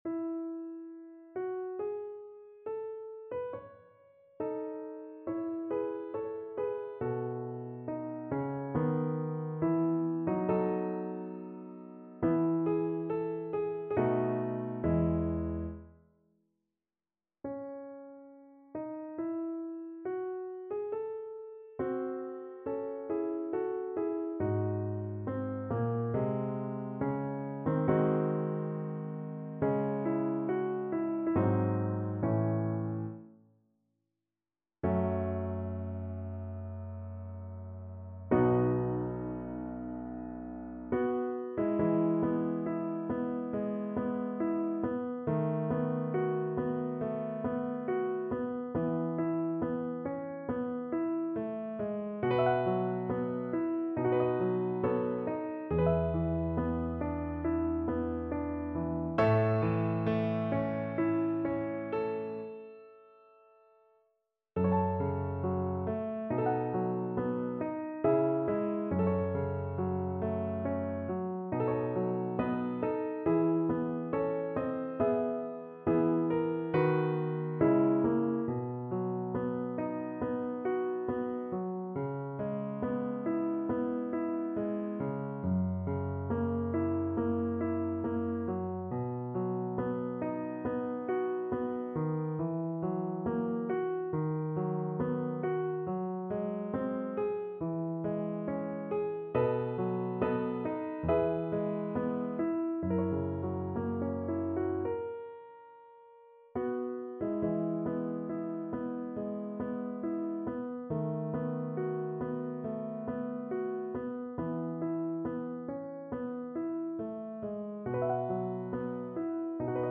E major (Sounding Pitch) (View more E major Music for Viola )
Larghetto (=80) =69
Classical (View more Classical Viola Music)